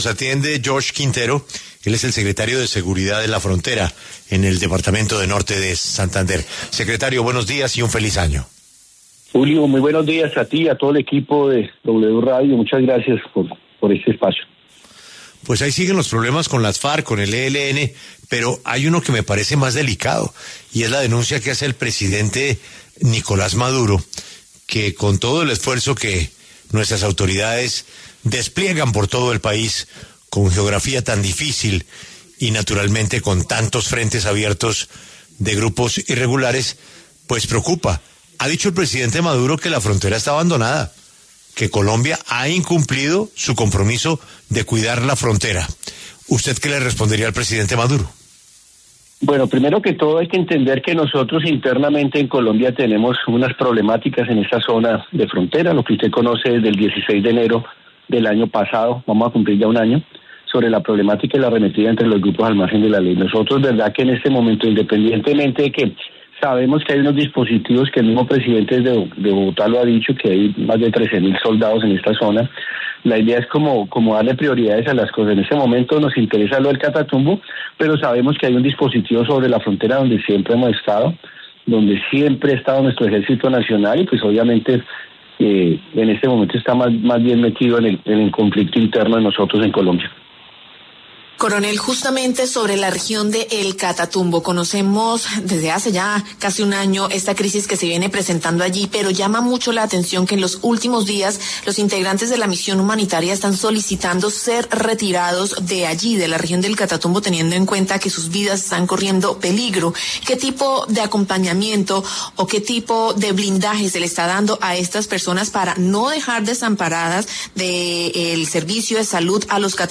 En entrevista con La W, el secretario de Seguridad de Norte de Santander, George Quintero, se refirió a la situación de violencia en el Catatumbo por parte del ELN y las disidencias de las Farc, territorio en el que se han presentado ataques con drones, combates, y se avizora una nueva crisis humanitaria.